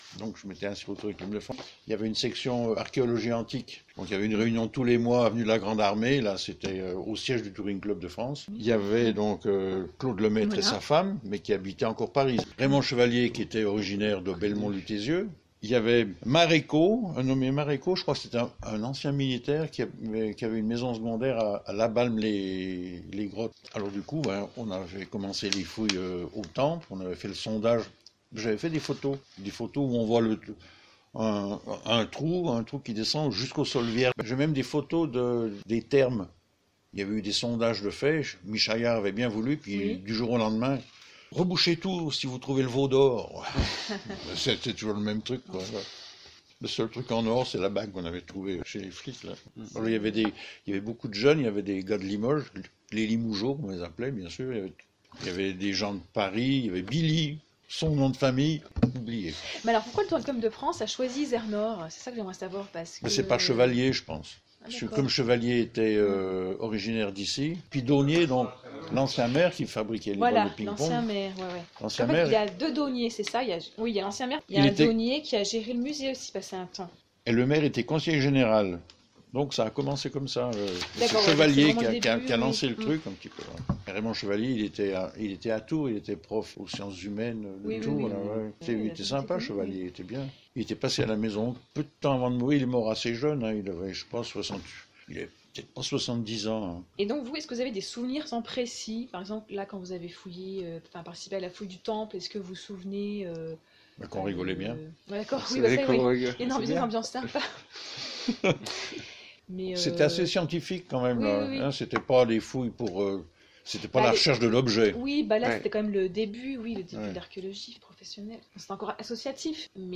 Audio : Interview d’un fouilleur du lotissement communal Transcription : Interview d’un fouilleur du lotissement communal